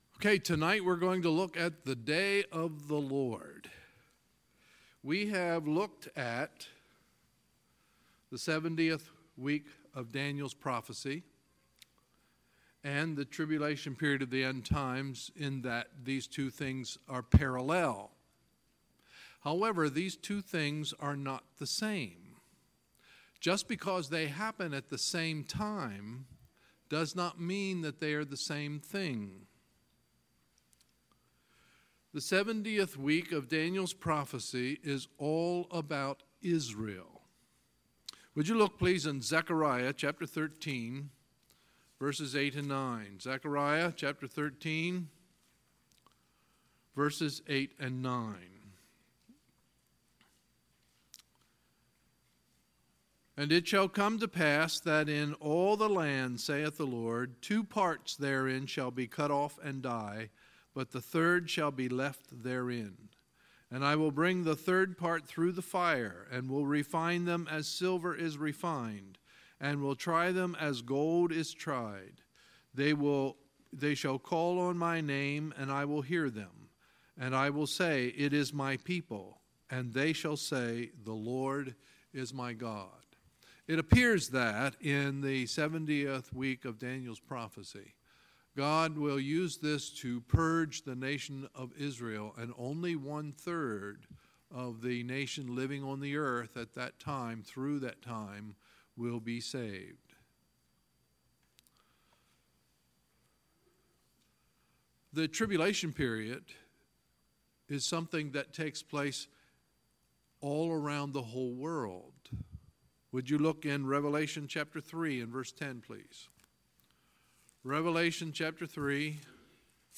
Sunday, October 7, 2018 – Sunday Evening Service